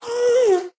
moan3.ogg